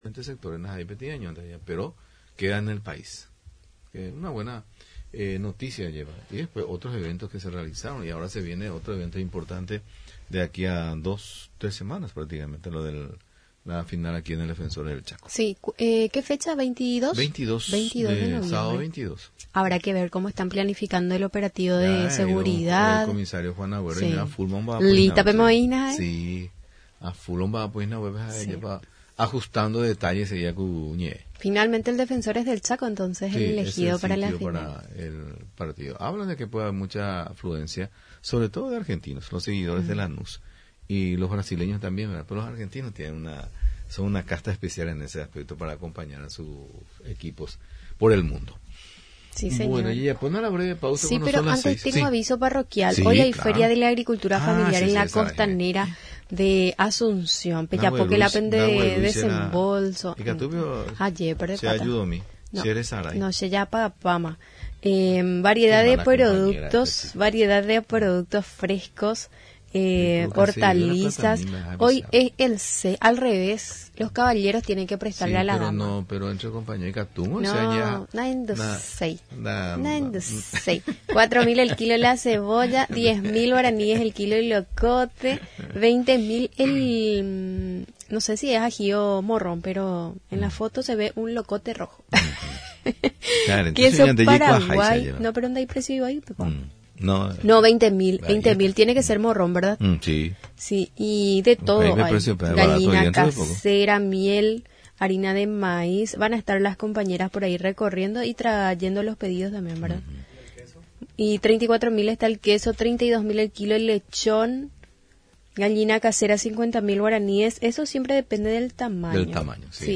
durante su visita a los estudios de Radio Nacional del Paraguay